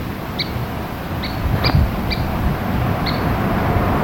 Couch’s Kingbird
St. Bernard